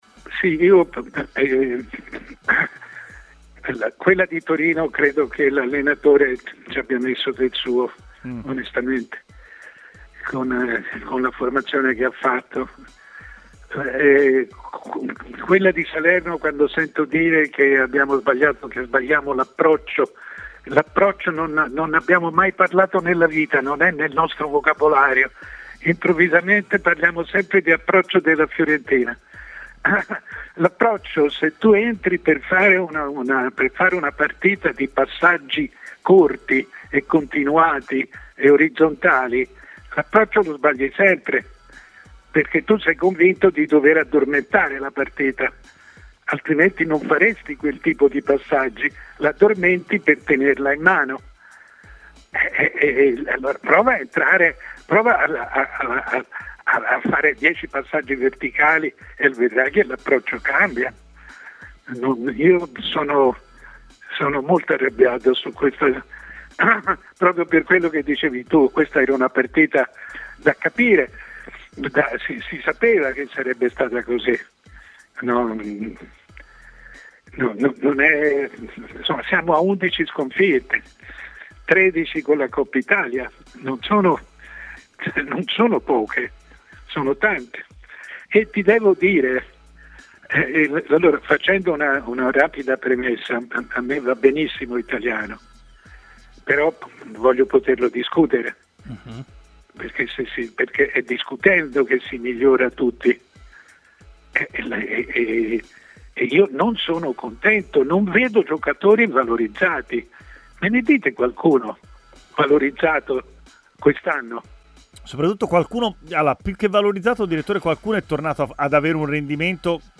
Intervistato all'interno di Stadio Aperto, il noto giornalista ed opinionista Mario Sconcerti ha parlato dei vari temi caldi legati alla Serie A, soffermandosi anche sul momento della Fiorentina, reduce dall'eliminazione in Coppa Italia contro la Juventus e dal brusco stop in campionato con la Salernitana: "Nella sconfitta di Torino (mercoledì con la Juve, ndr) credo che l'allenatore ci abbia messo del suo con la formazione.